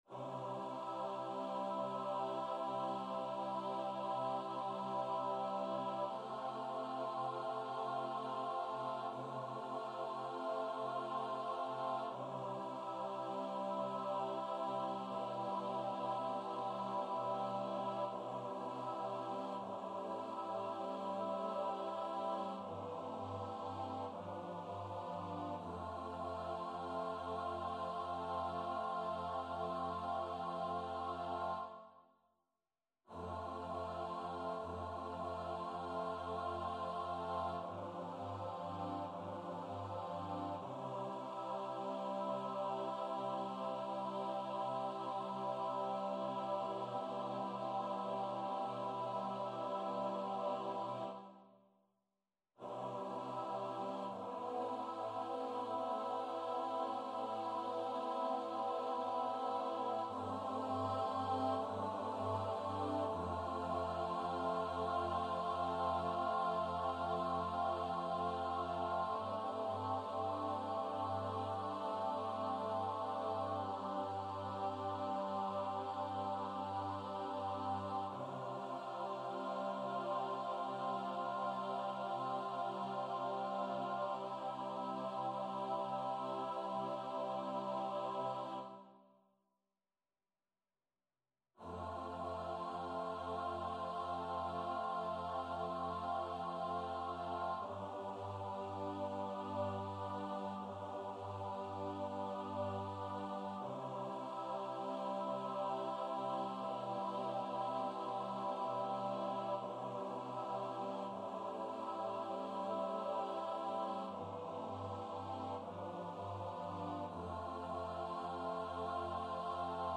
liturgical , SATB